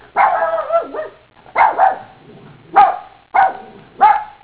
Puppy
PUPPY.wav